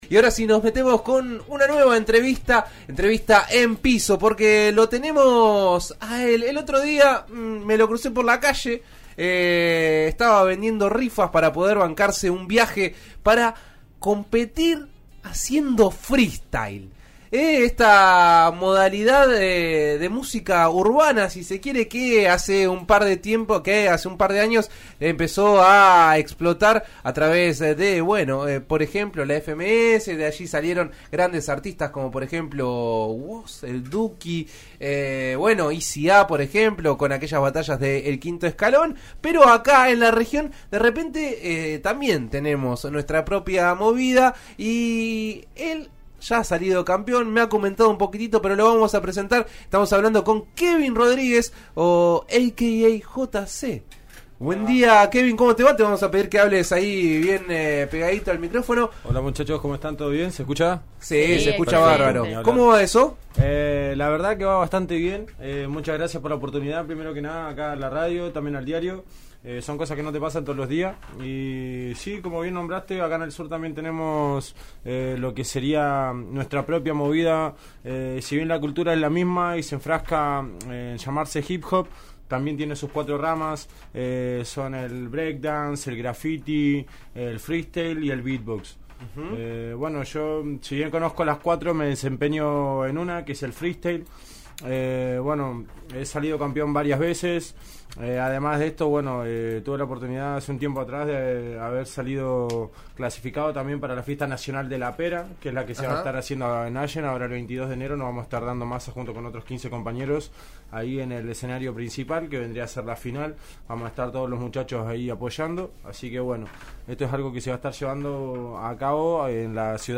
El artista local visitó el estudio de RÍO NEGRO RADIO. Escuchá la entrevista con 'En eso estamos'.